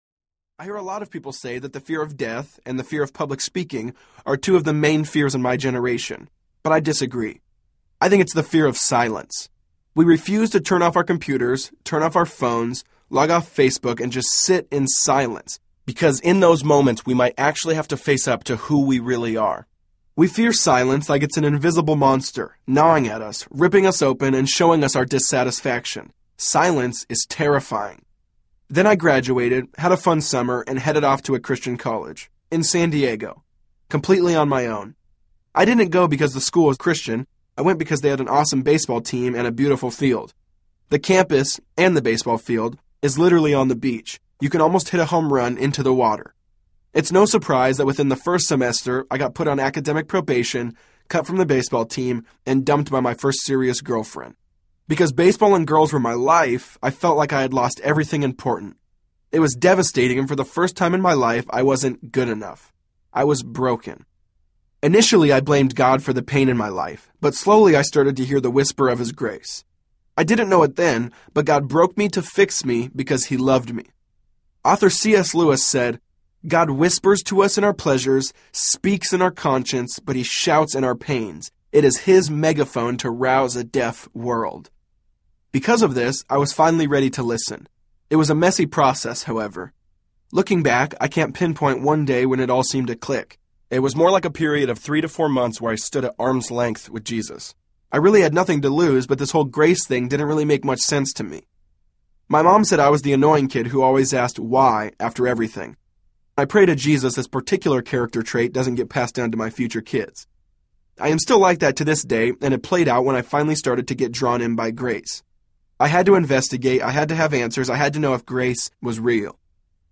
Jesus > Religion Audiobook
4.2 Hrs. – Unabridged